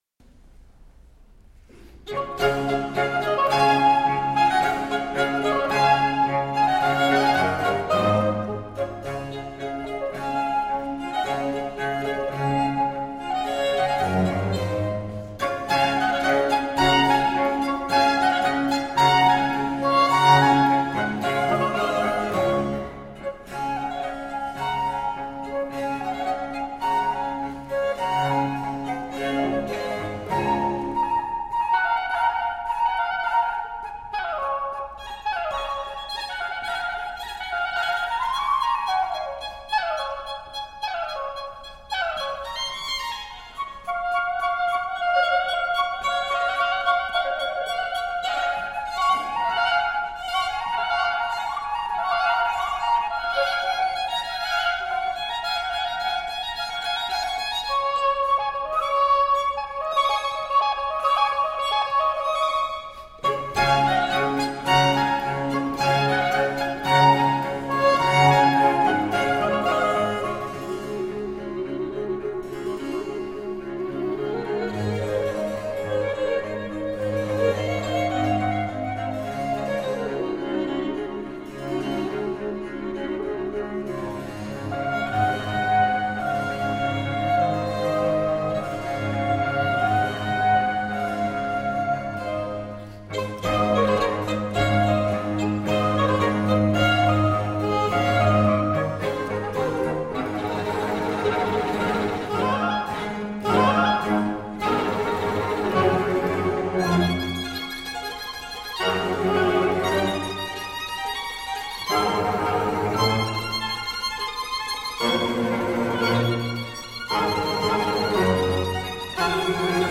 Spectacular baroque and classical chamber music.